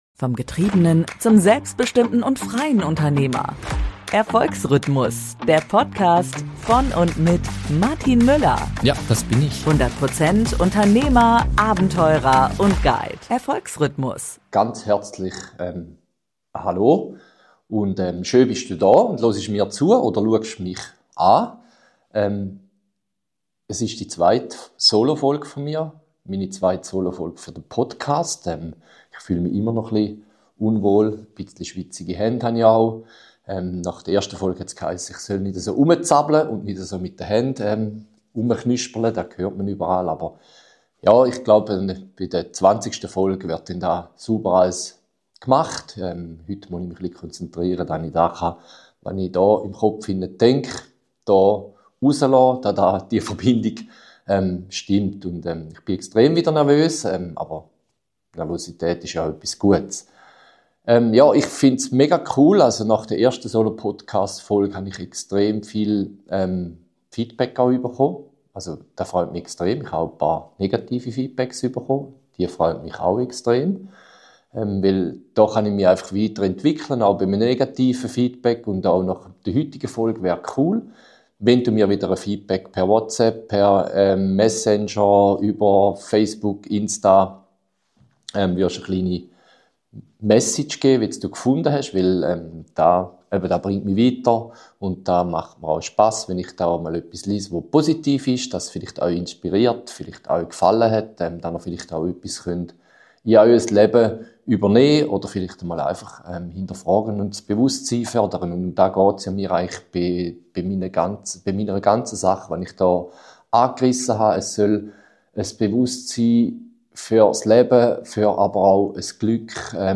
In dieser Solo-Podcast-Folge spreche ich über das Thema Freiheit